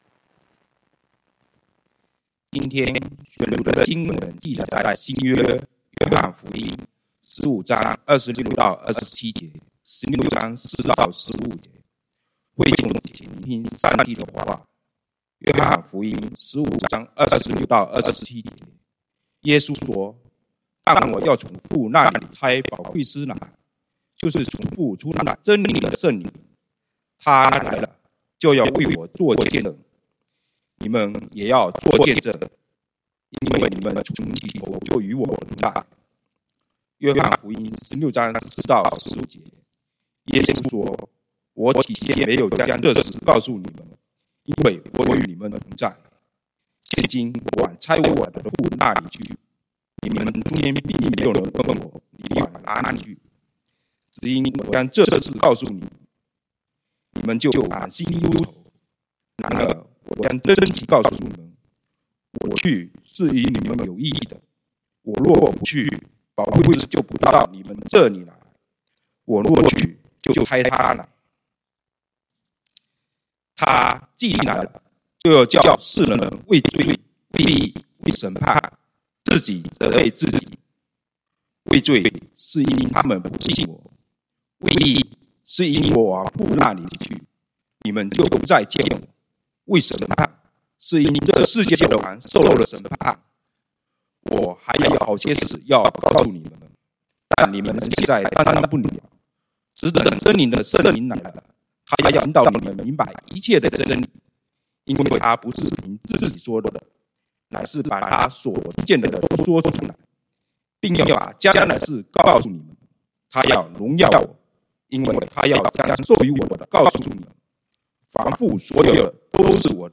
講道經文：約翰福音 John15:26-27, 16:4b-15